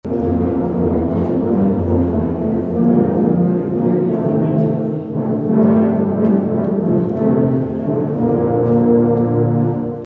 Dozens of tuba and euphonium players gathered at Manhattan Town Center Saturday for TubaChristmas.